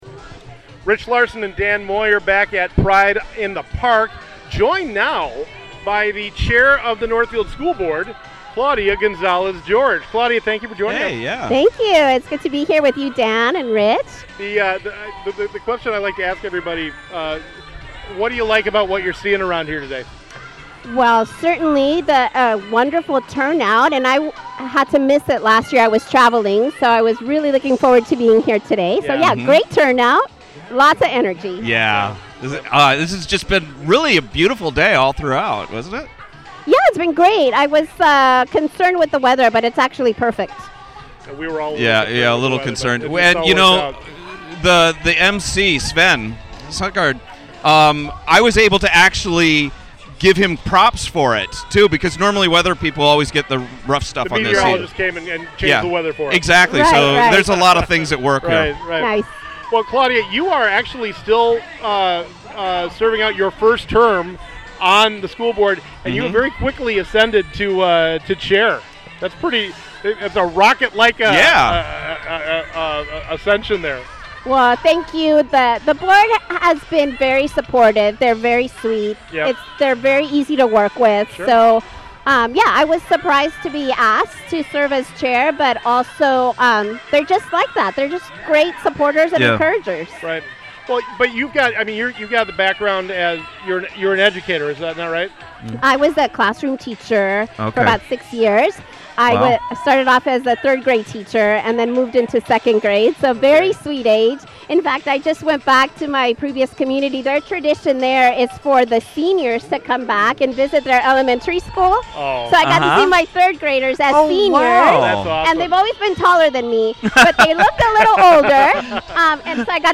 Northfield School Board Chair Claudia Gonzalez-George discusses issues for LGBTQ+ youth from Northfield’s Pride in the Park Celebration.